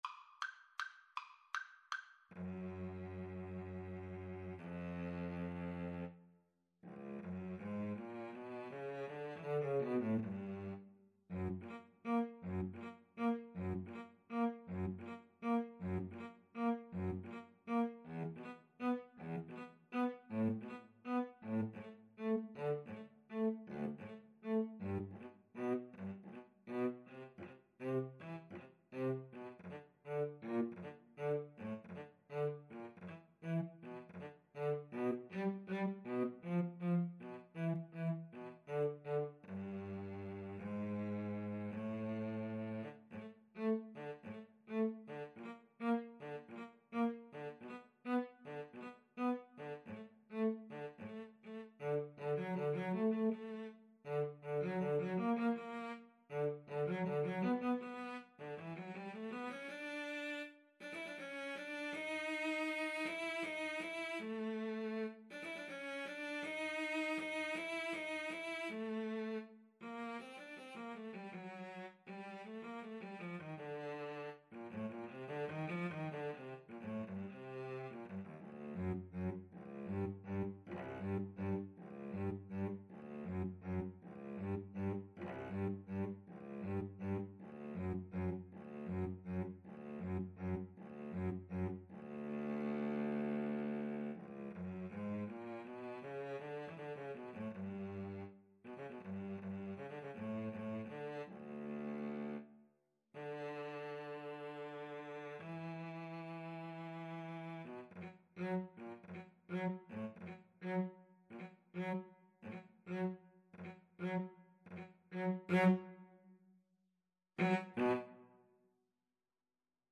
Play (or use space bar on your keyboard) Pause Music Playalong - Player 1 Accompaniment reset tempo print settings full screen
D major (Sounding Pitch) (View more D major Music for Flute-Cello Duet )
Allegretto = 160
Classical (View more Classical Flute-Cello Duet Music)